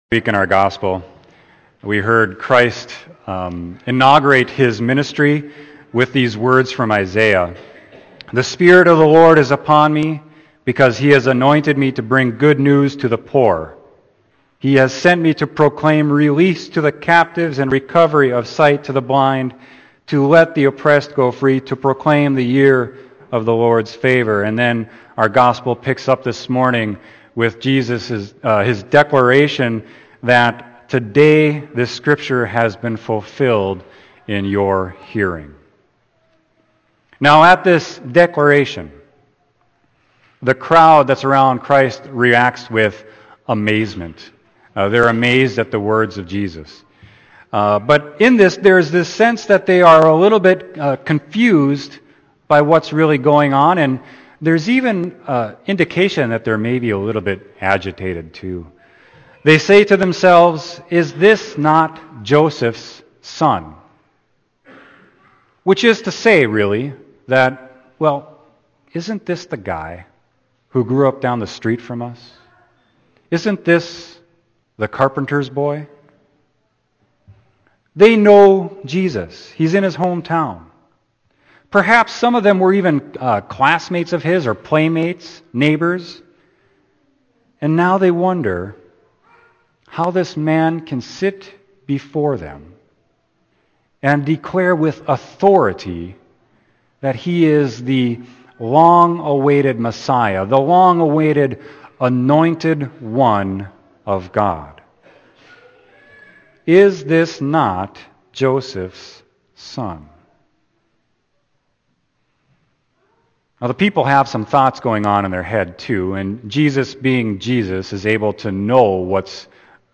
Sermon: Luke 4.21-30